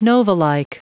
Prononciation du mot novalike en anglais (fichier audio)
Prononciation du mot : novalike